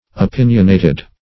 Opinionated \O*pin"ion*a`ted\, a.